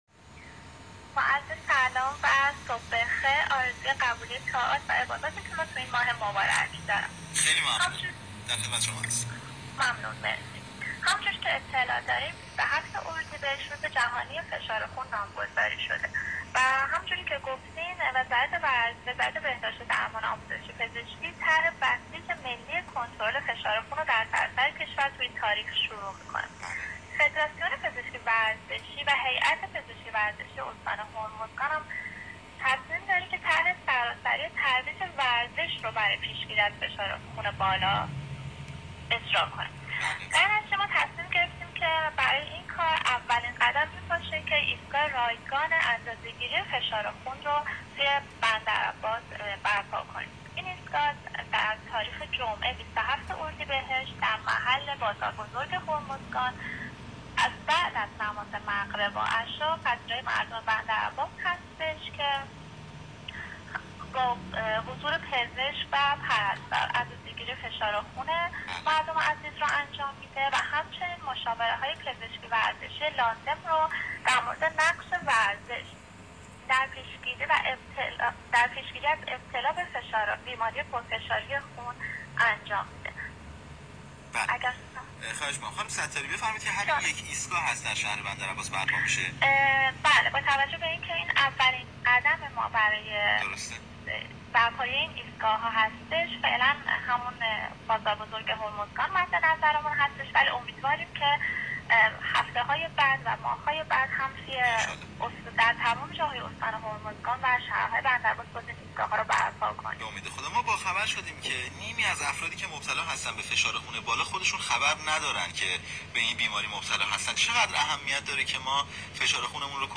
گفت و گوی رادیویی / آموزشی